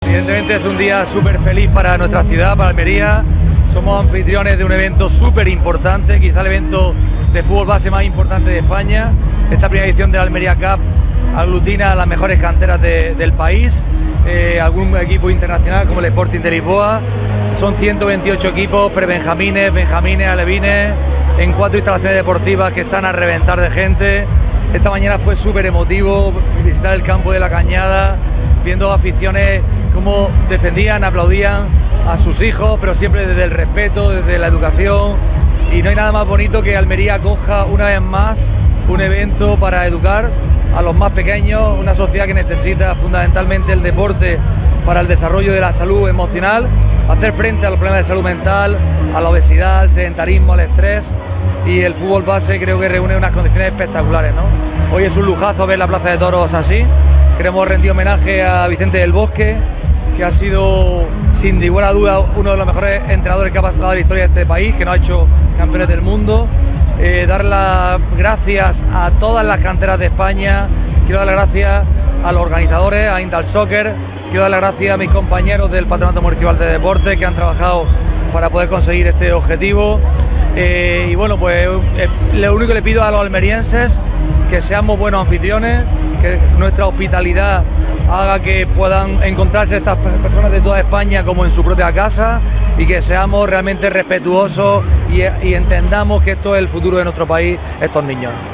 ANTONIO-JESUS-CASIMIRO-CONCEJAL-CIUDAD-ACTIVA-GALA-ALMERIA-CUP.wav